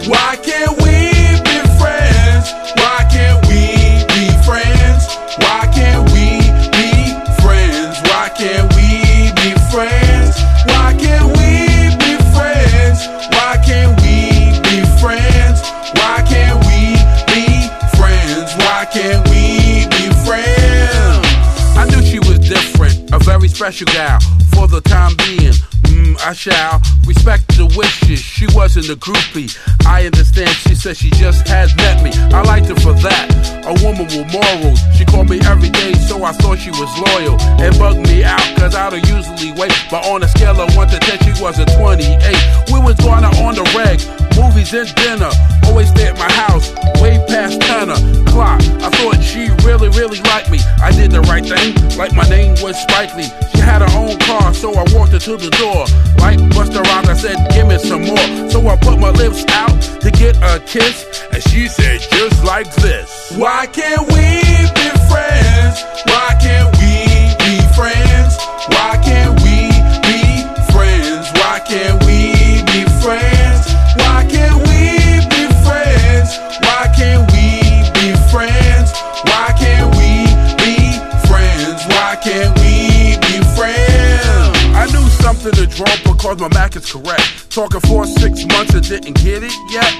GARAGE PUNK / INSTRO
四日市で結成されたガレージ・ギター・インスト・トリオ！
焦げ臭いファズ・ギターとドカドカ・ブンブン唸るリズム隊をもってアグレッシヴにプレイしています！